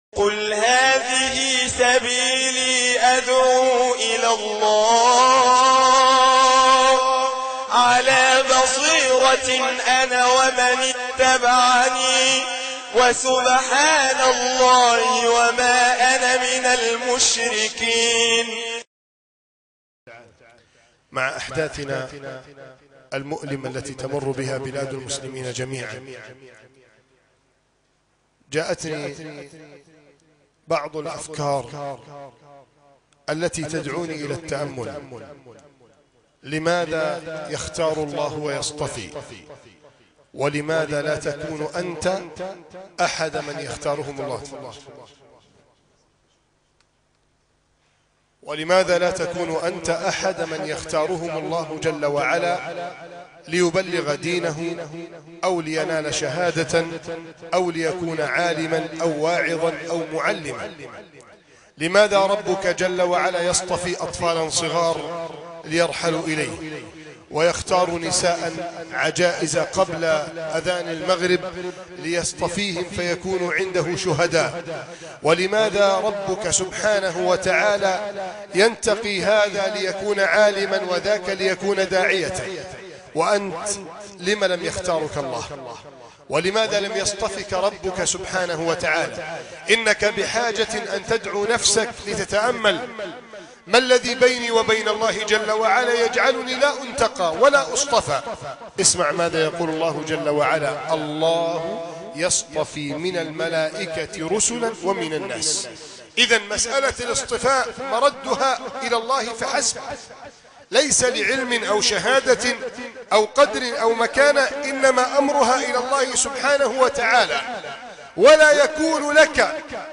لماذا يختار الله ويصطفي ؟ ( مسجد التابعين - بنها